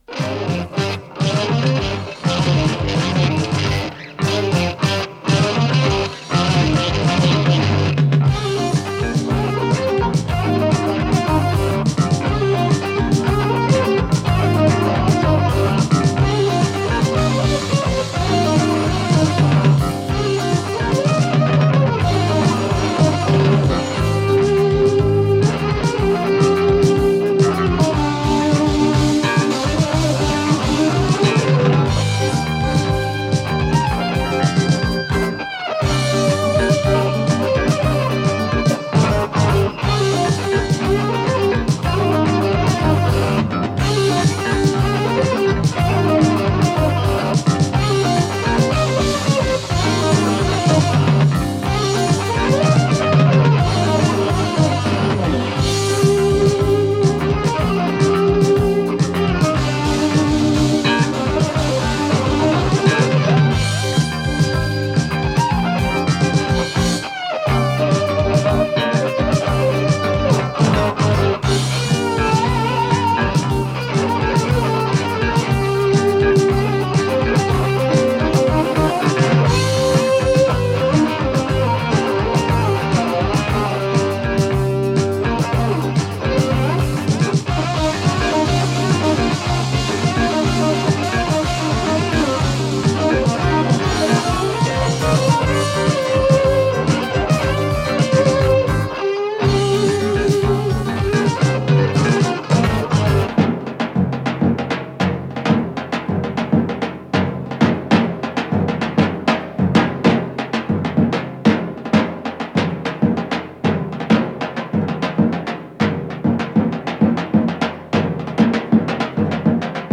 с профессиональной магнитной ленты
ВариантДубль моно